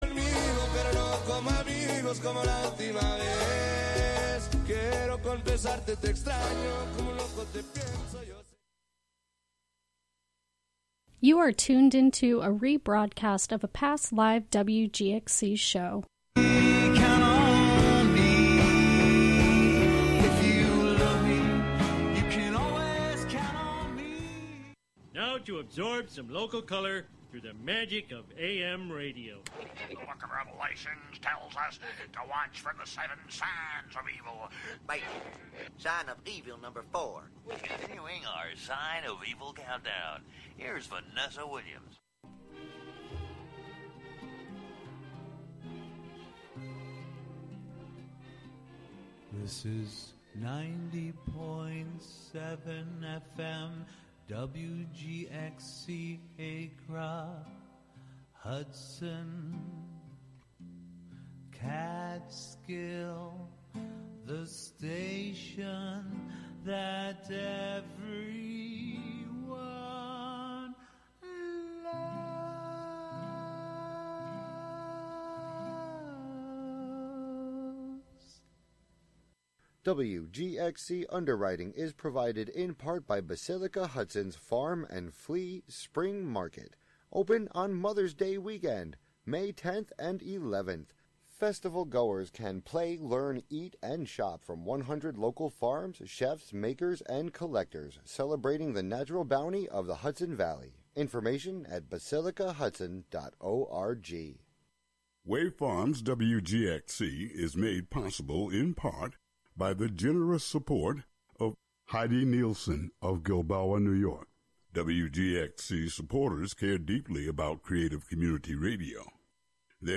Live from the Catskill Maker Syndicate space on W. Bridge St. in Catskill, "Thingularity" is a monthly show about science, technology, fixing, making, hacking, and breaking with the amorphous collection of brains comprising the "Skill Syndicate."